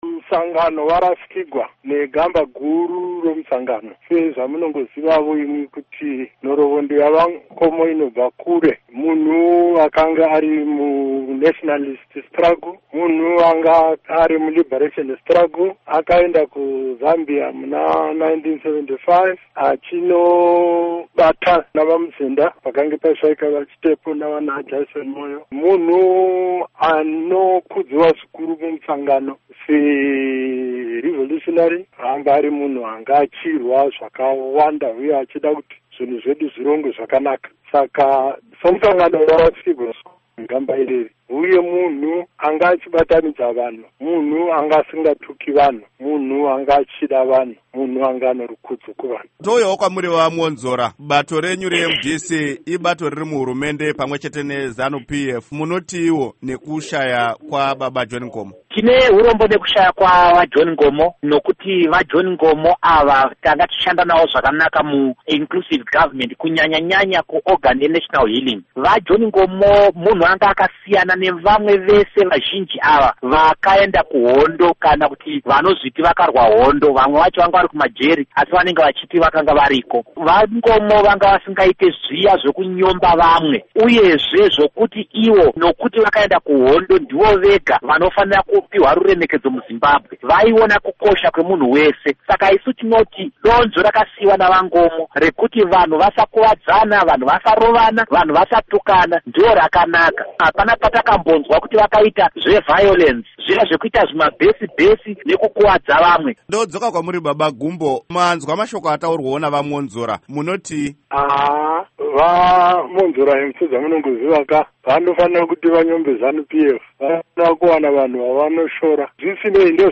Hurukuro naVaRugare Gumbo pamwe naVaDouglas Mwonzora